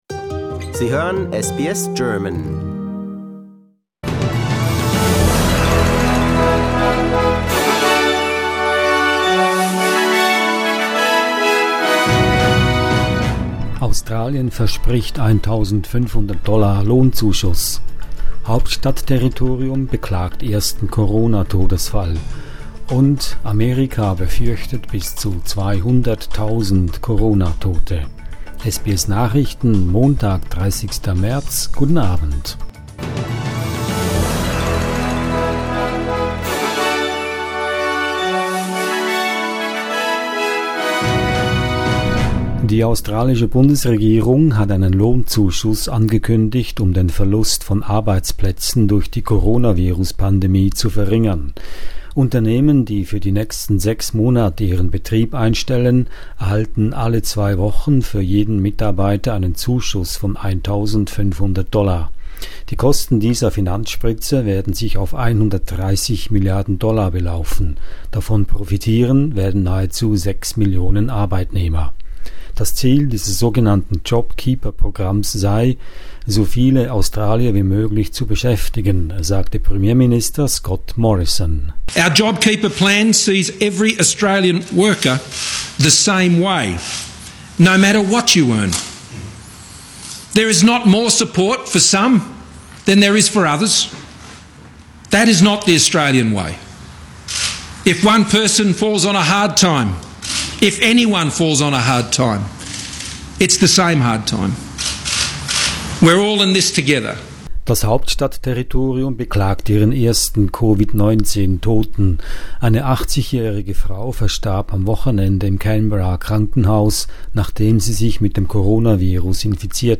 SBS Nachrichten, Montag 30.03.20